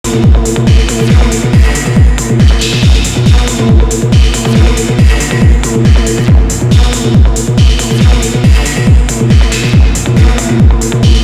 To be honest, sometimes it’s worse, but here’s a bunch of snippets I just recorded, taken from my last improv practice session.
Hearing it back it’s less worse then I sometimes imagine, but it does have this barrage of short staccato 16ths constantly.